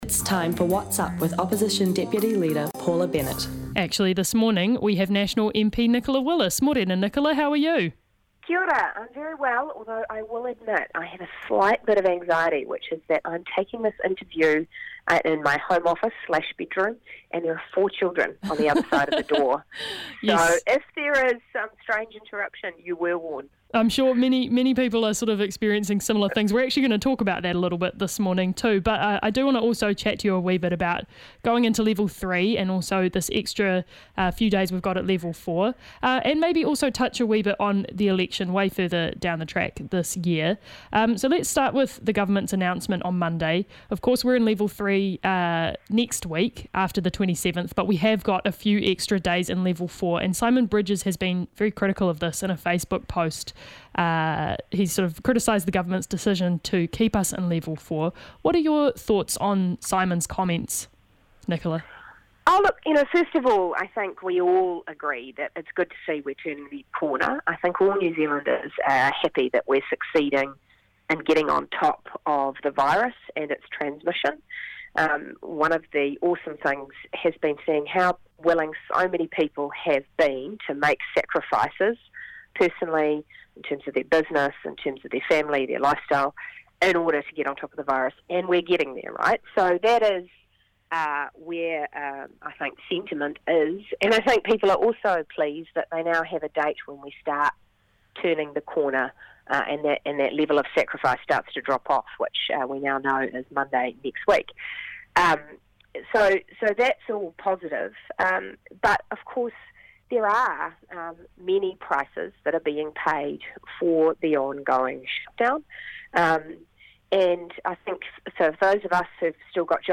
National MP, Nicola Willis